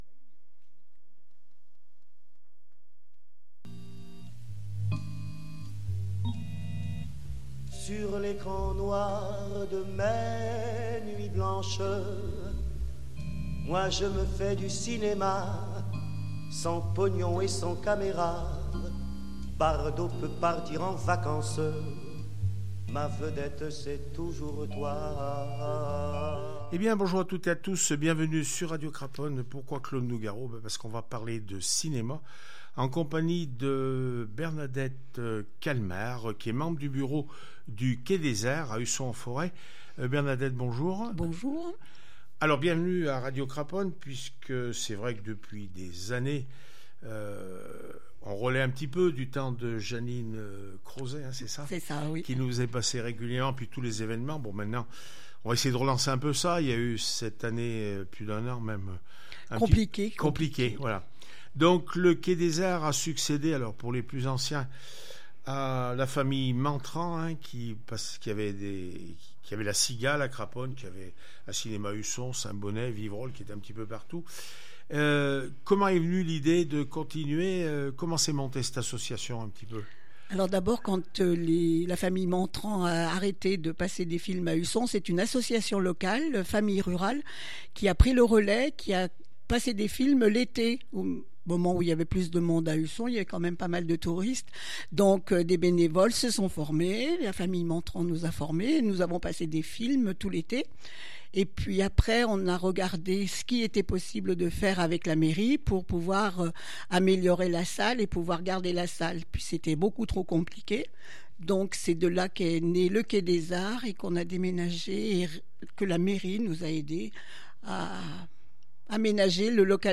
cinéma le quai des arts a Usson en forez entretien